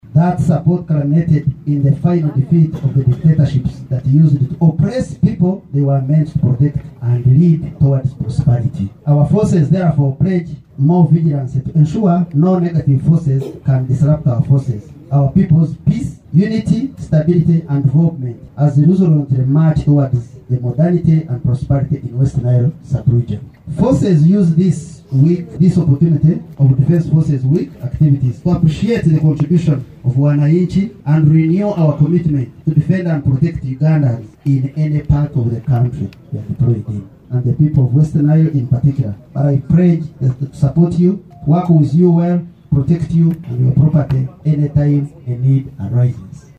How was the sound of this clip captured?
The pledge was made during a ceremony held on February 6th, 2024, at the military headquarters of West Nile in Arua City.